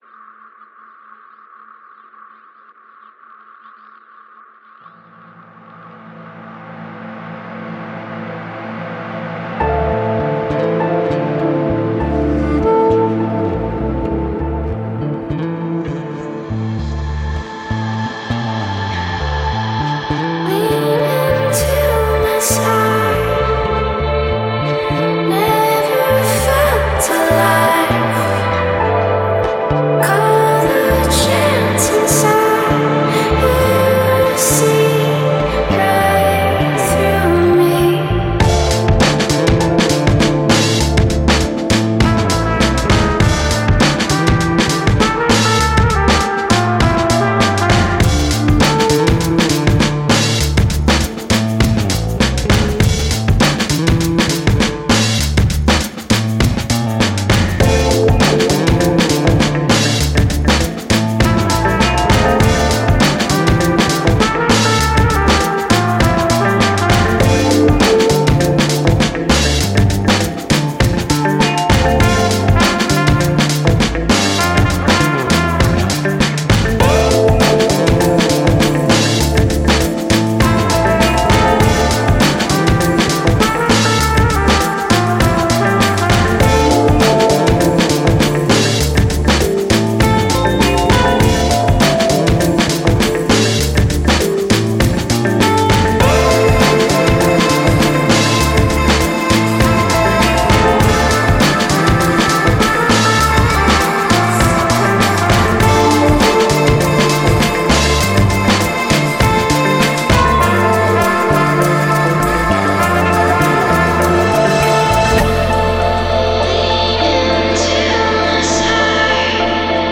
This soothing trip-hop oriented track […]